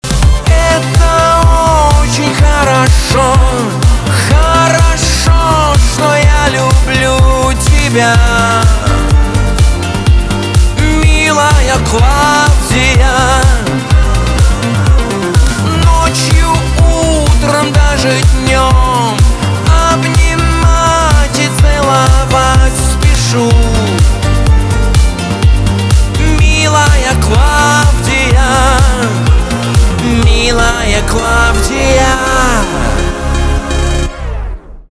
поп
битовые , басы
пианино